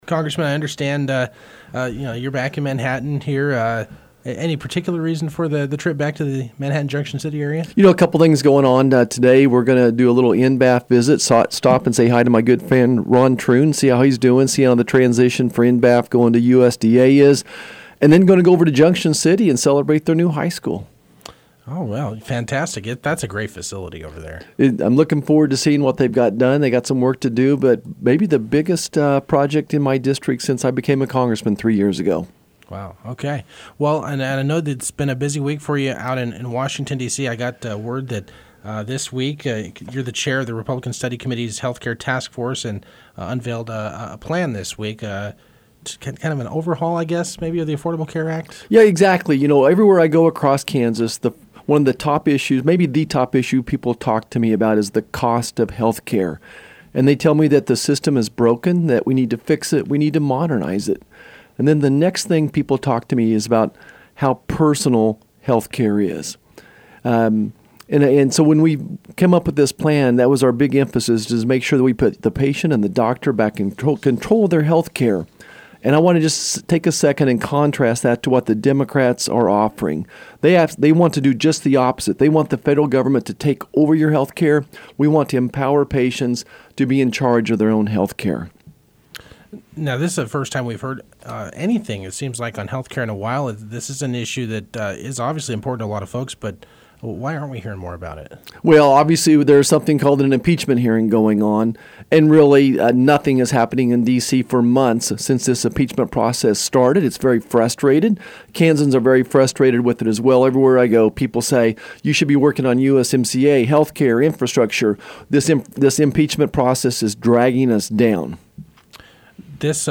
Congressman Roger Marshall visited the KMAN studios Friday, Oct. 25.
“They want to do just the opposite. They want the federal government to take over your healthcare. We want to empower patients to be in charge of their own healthcare,” Marshall said during an interview Friday on News Radio KMAN.
The full interview can be found below.